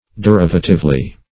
-- De*riv"a*tive*ly, adv.